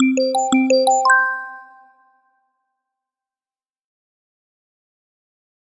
زنگ موبایل اس ام اس کوتاه آهنگ هشدار